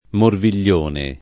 morviglione [ morvil’l’ 1 ne ]